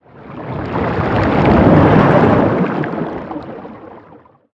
Sfx_creature_hiddencroc_swim_slow_03.ogg